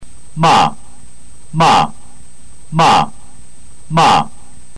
第四声(だいよんせい)）：高い音から一気に低い音へ下げる。
中国語の発音をクリックすると「ma」の各声調の音を４回繰り返して聞くことができますので何度も聞いて確実にマスターしてください。
ma4.mp3